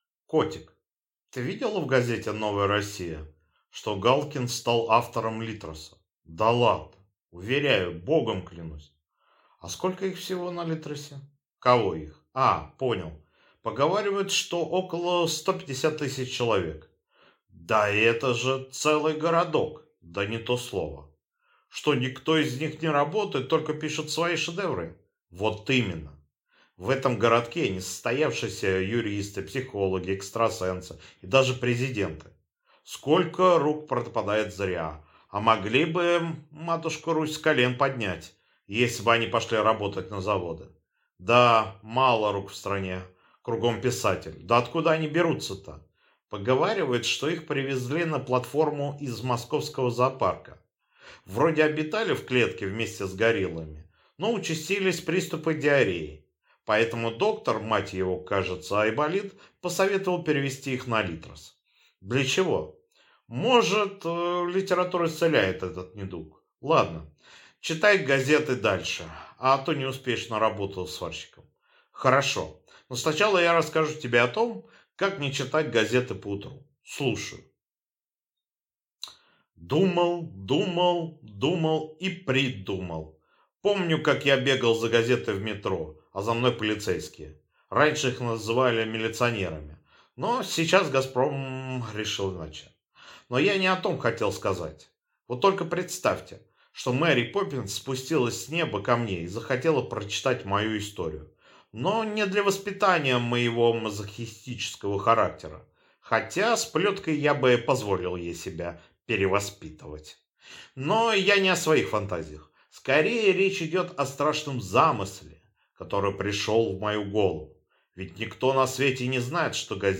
Аудиокнига Словарь Авторов Литреса, или Как не читать газеты поутру | Библиотека аудиокниг